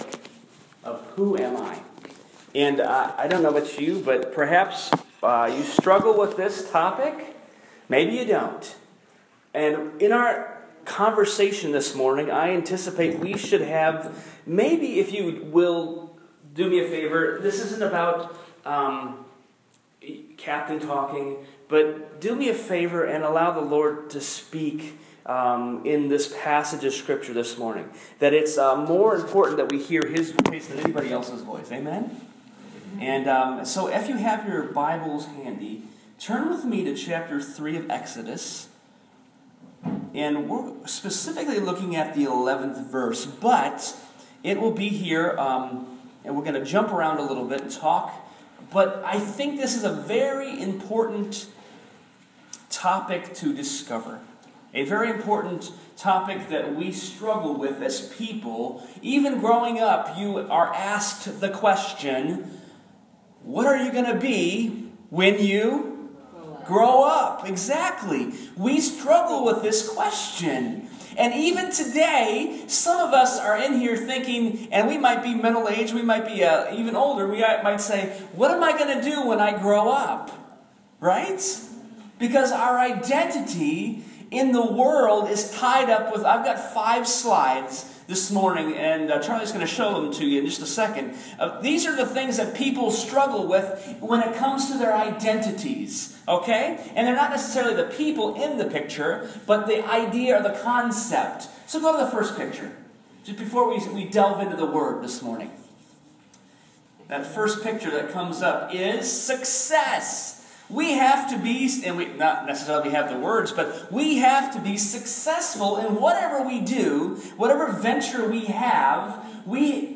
Sermon Podcast – “Who Am I?”
who-are-you-sermon.m4a